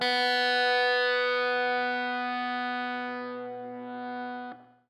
SPOOKY    AL.wav